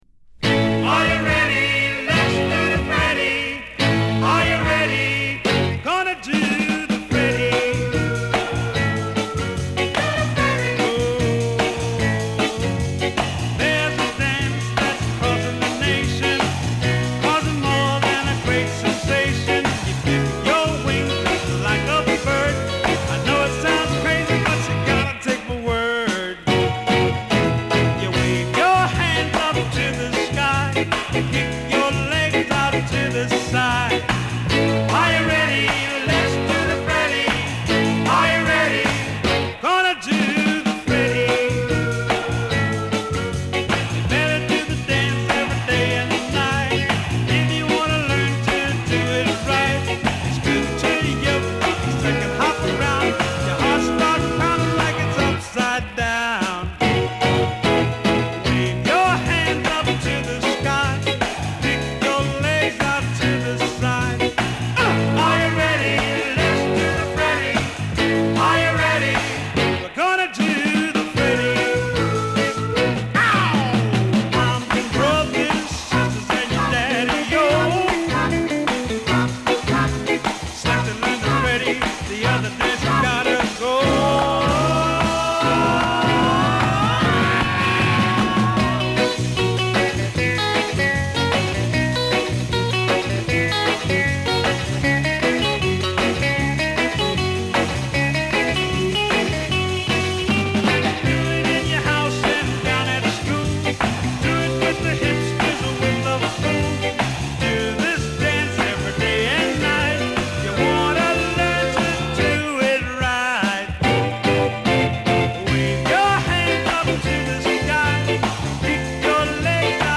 キャッチーだが涼しげな演奏で気持ちいい。